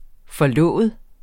Udtale [ fʌˈlɔˀvəð ]